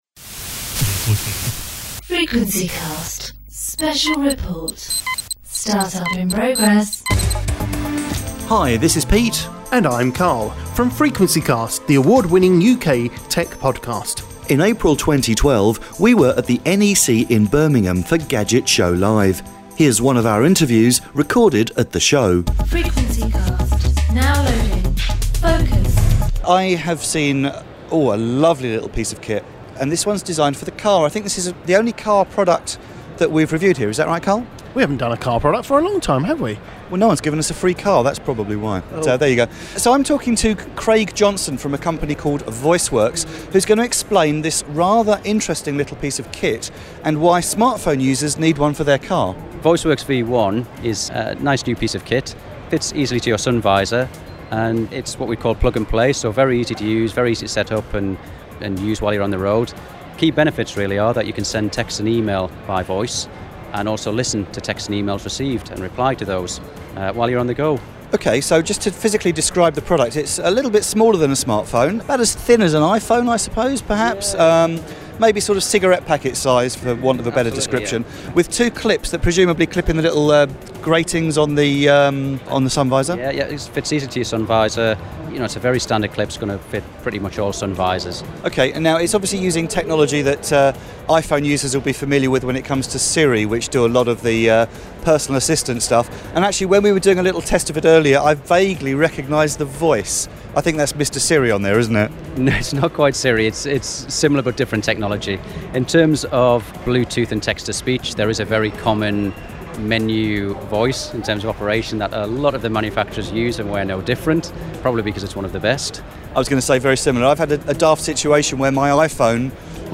Voiceworks V1 Bluetooth Hands-free Kit - Interview
We tried this in a very noisy conference hall in Birmingham, and we're happy to report that the email dictated at the show actually made it through to us.